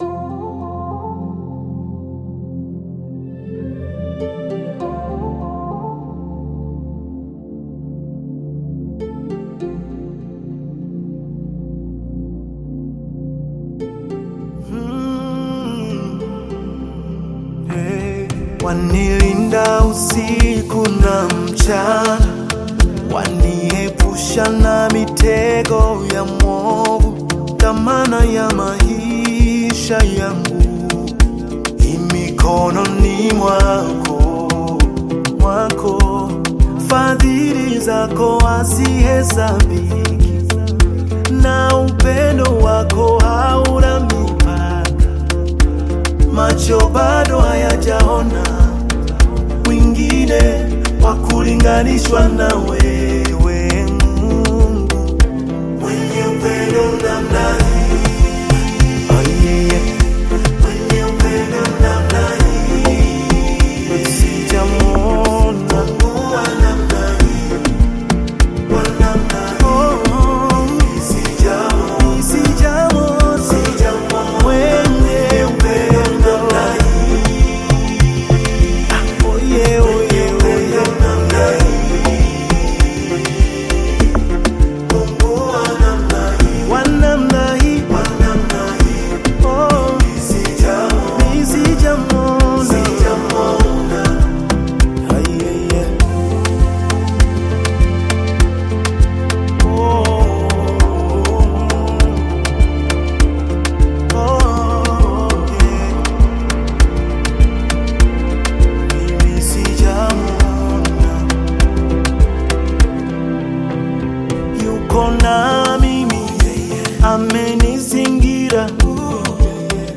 Gospel music track
Gospel song